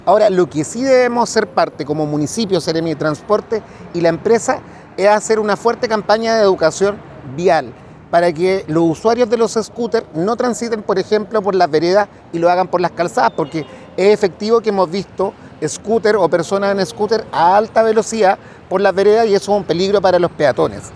Por su parte, el concejal Óscar Ramírez coincidió en que se han registrado problemas con el uso de los sccoters, pero que se requiere realizar campañas de educación vial junto municipio y la seremi de Transportes.
cuna-scooter-oscar-ramirez.mp3